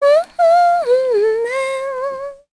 Shamilla-Vox_Hum_kr.wav